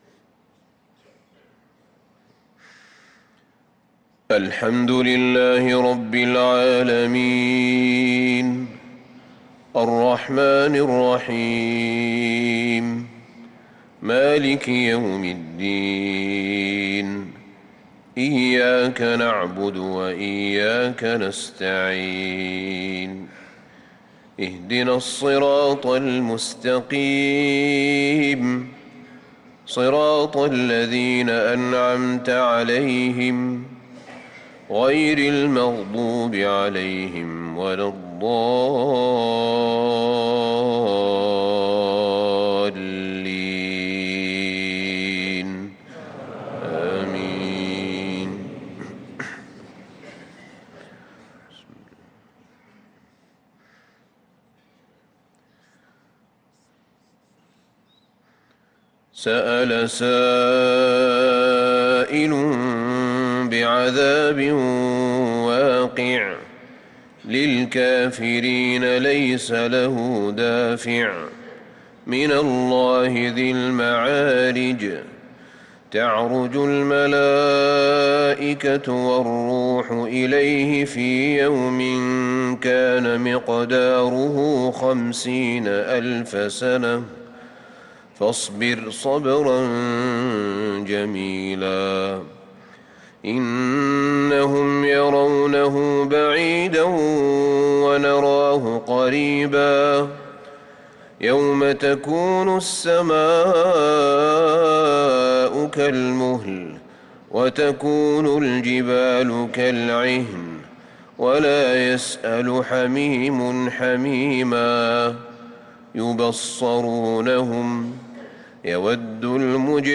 صلاة الفجر للقارئ أحمد بن طالب حميد 8 رجب 1445 هـ
تِلَاوَات الْحَرَمَيْن .